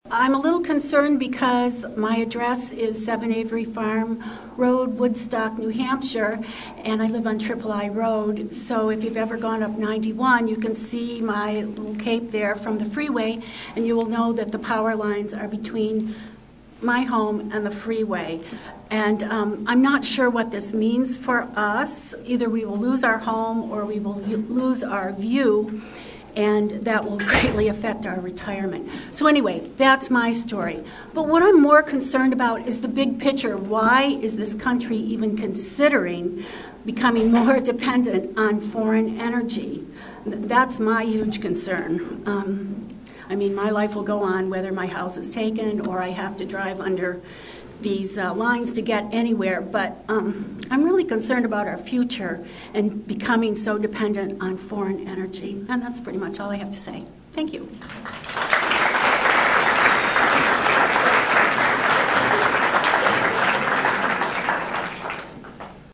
Public Scoping Meeting- Haverhill 3/20/11: